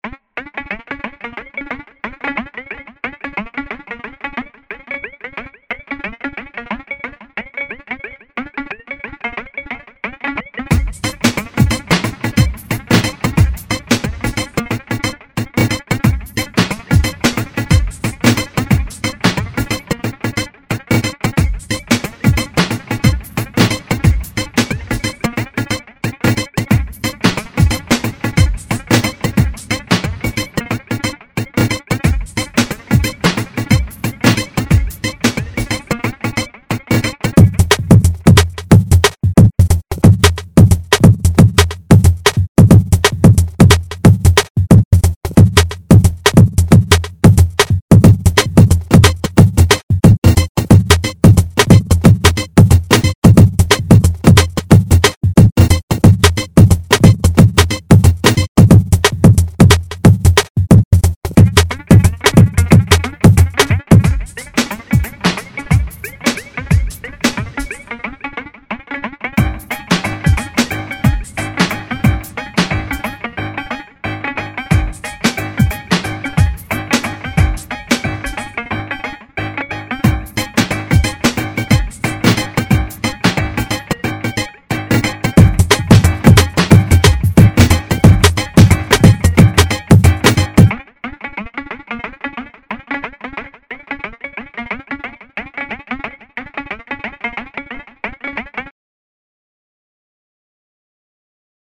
He created a bunch of loops that you can use in your own compositions if you want.